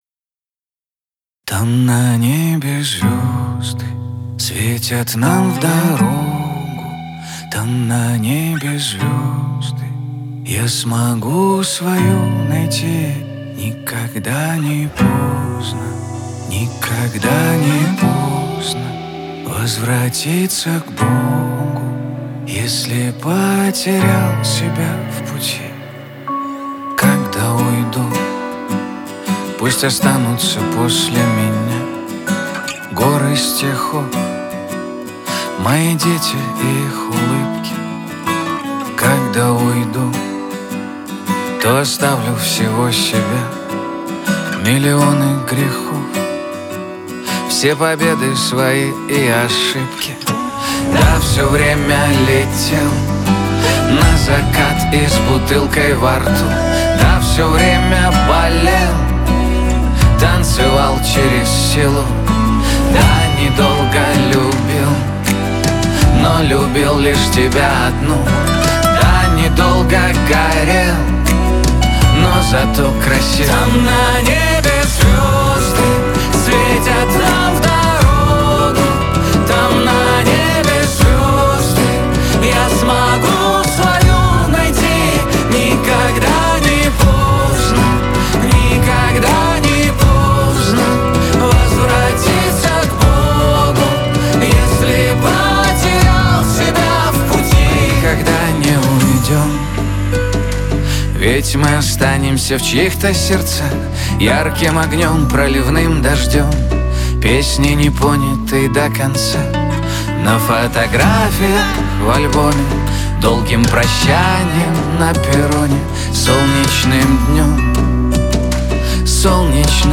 Жанр: Новинки русской музыки